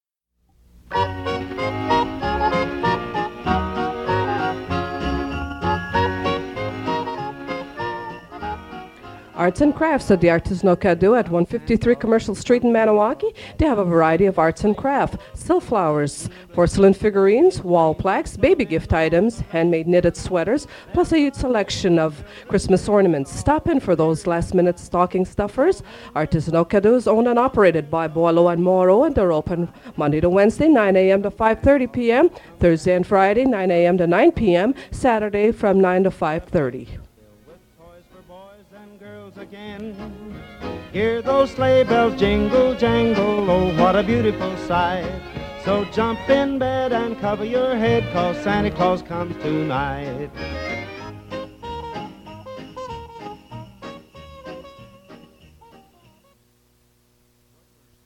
Fait partie de Commercial announcement and festive message